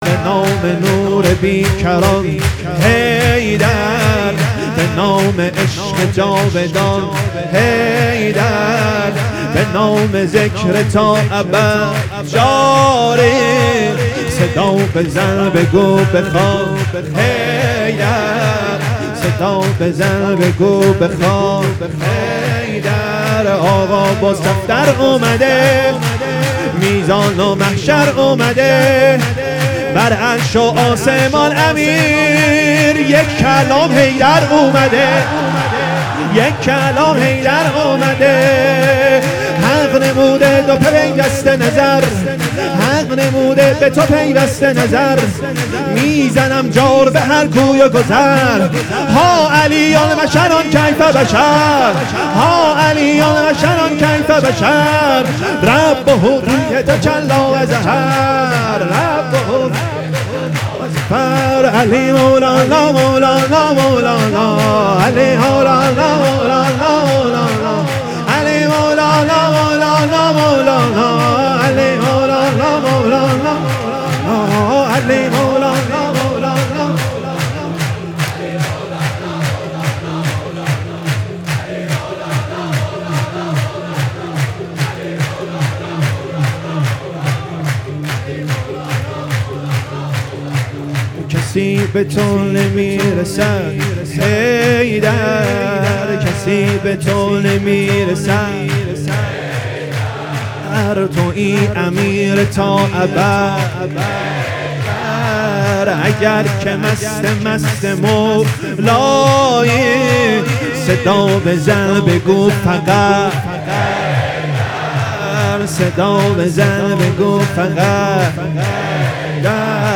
ولادت امام علی(ع)